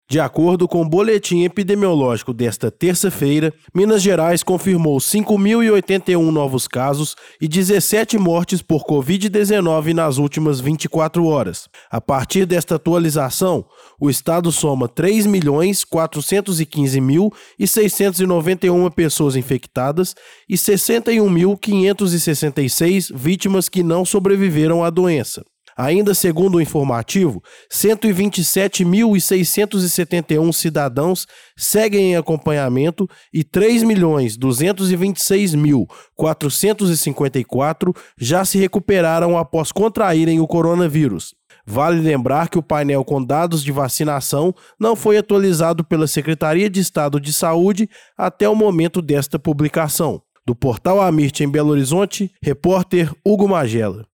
Boletim: mais de 5 mil casos são confirmados em MG nas últimas 24 horas